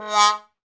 Index of /90_sSampleCDs/Roland L-CDX-03 Disk 2/BRS_Trombone/BRS_TromboneMute